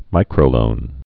(mīkrō-lōn)